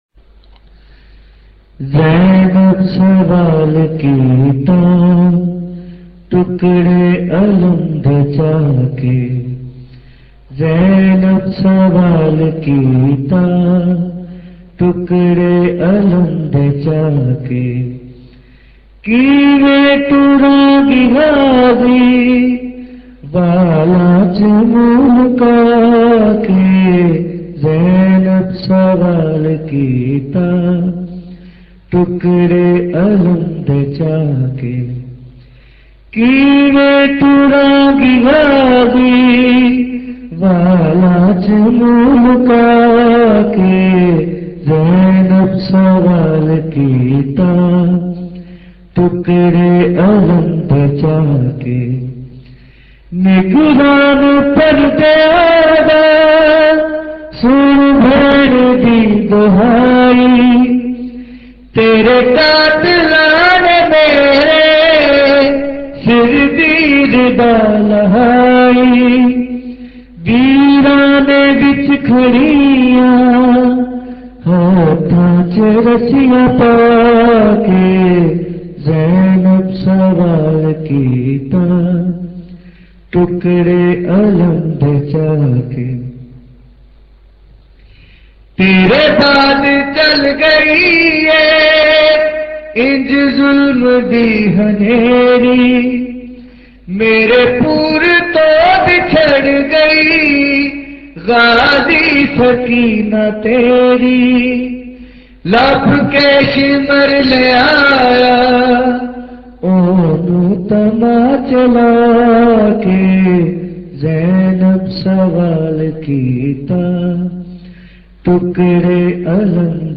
Recording Type: Live